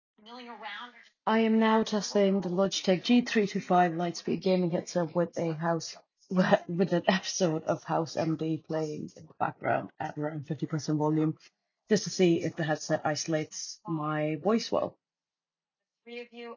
This can be disadvantageous, but the G325 Lightspeed features AI-powered noise reduction, which helps reduce some ambient noise. I tested this by playing an episode of House M.D. on my phone in the background, and while the headset does a decent job of highlighting my voice, there is some muddiness where it sounds like my voice and the characters’ voices are bleeding into one another.